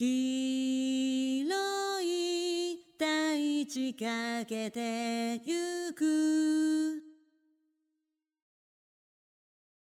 まずは、メロディのフレーズをベタ打ちで打ち込みます。
この状態で再生すると次のような歌唱表現になります。
vocal_edit_before.mp3